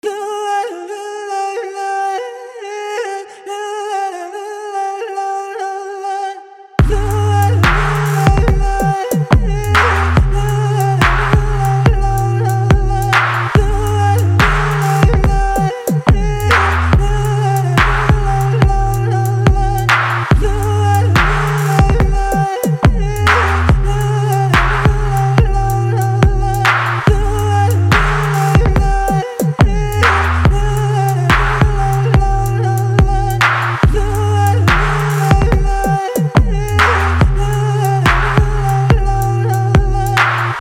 به همراه بیت